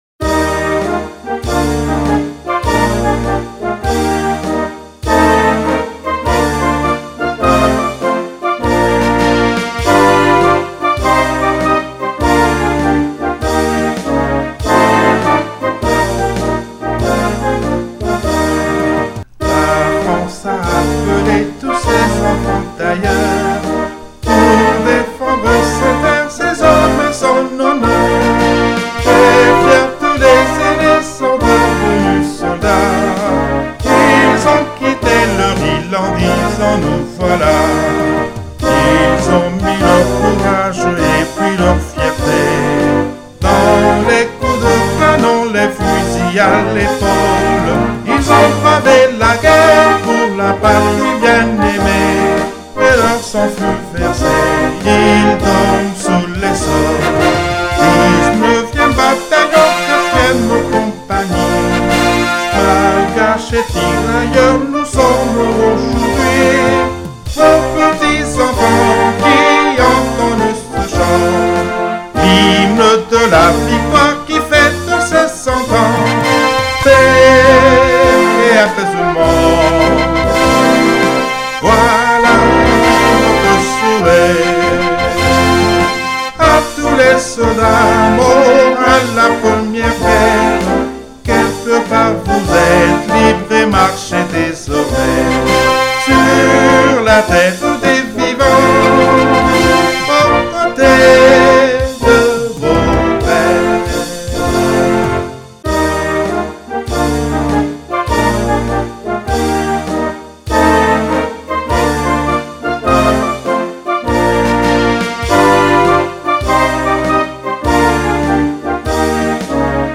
HYMNE CENTENAIRE CHANTEE